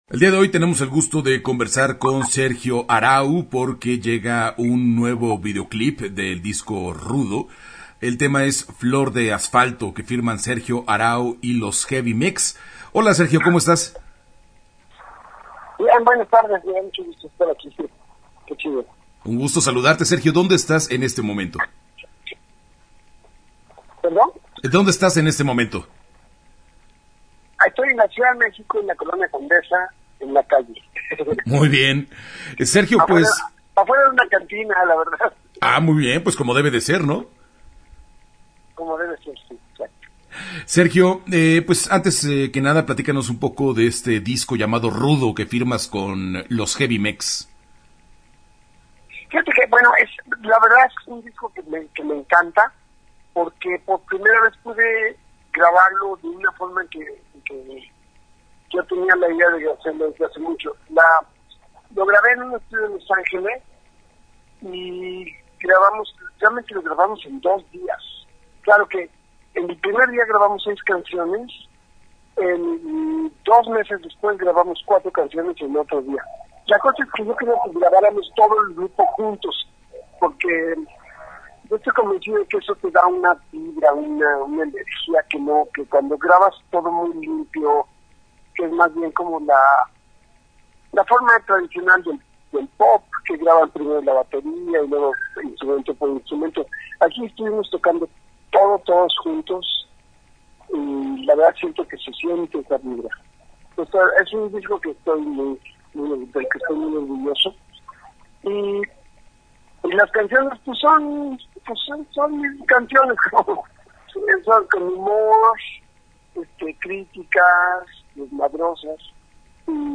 Te invitamos a escuchar nuestra charla con Sergio Arau.
Entrevista-Sergio-Arau-web.mp3